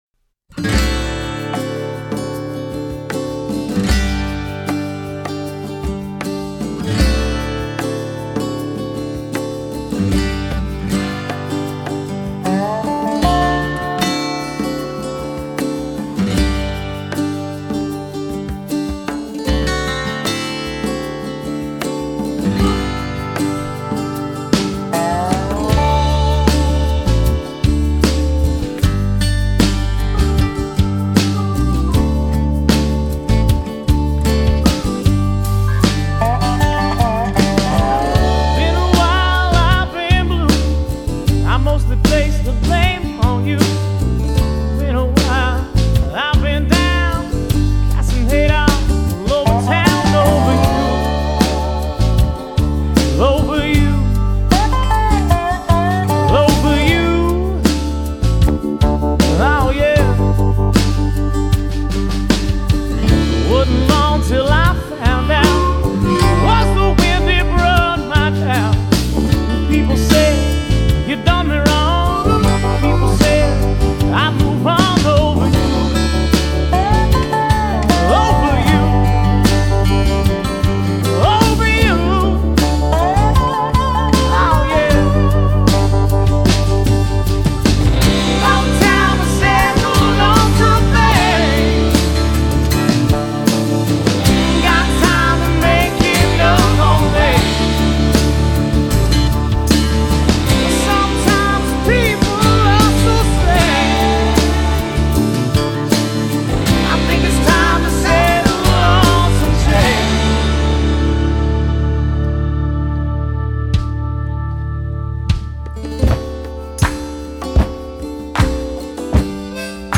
Genre: Blues / Southern Rock
Vocals, Guitar, Harmonica